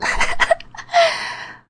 nya_happy_4.ogg